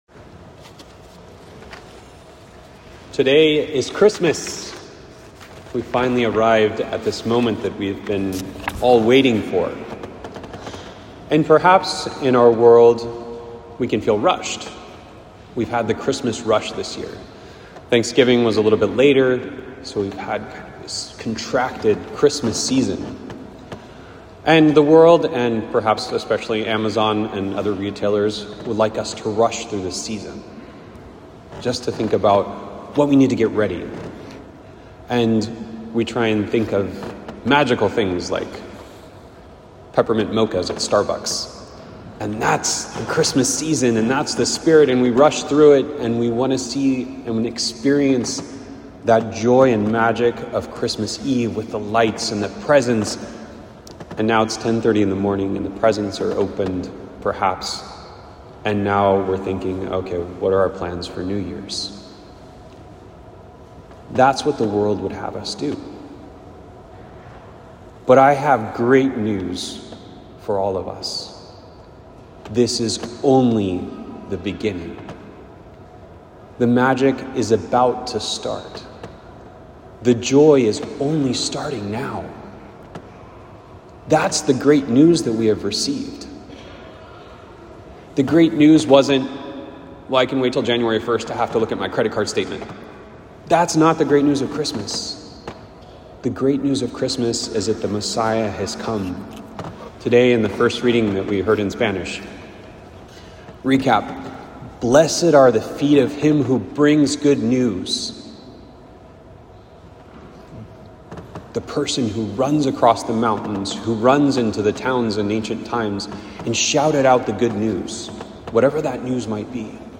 homily for December 25th, 2025.